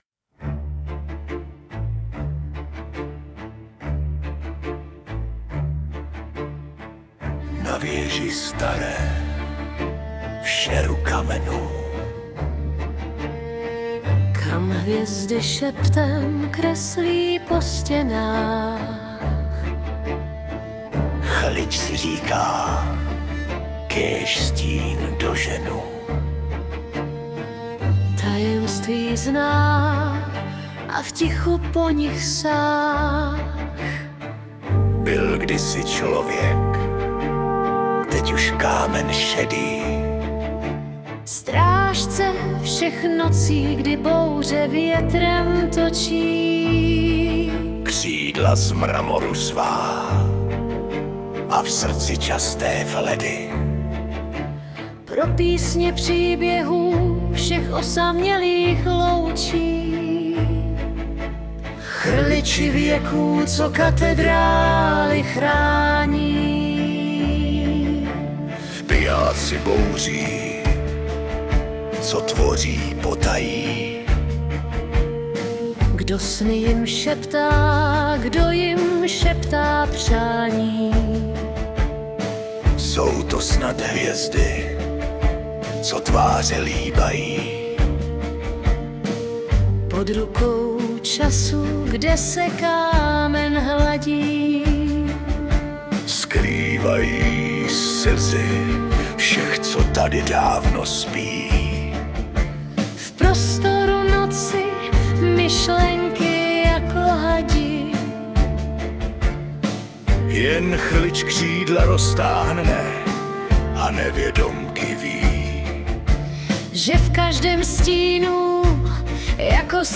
Balady, romance » Fantasy
Pomalé, mystické intro – zvuk varhan, tikání času, ševel listí a nočního větru…
Pak tiché slovo – šepot:
Pomalu doznívají varhany… a v dálce už jen vítr šeptá příběh dál